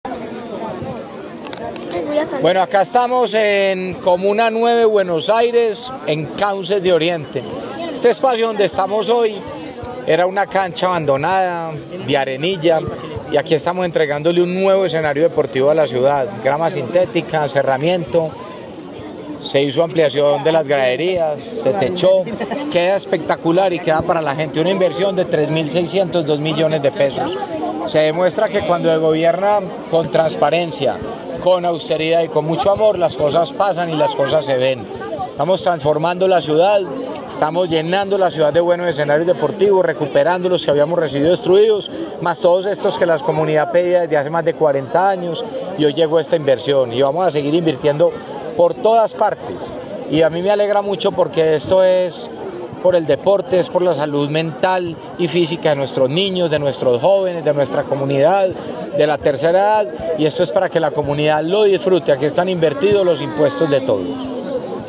Declaraciones-alcalde-de-Medellin-Federico-Gutierrez-3.mp3